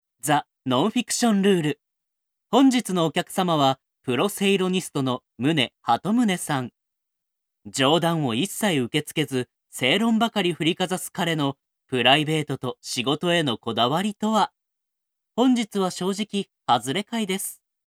– Narration –
Straight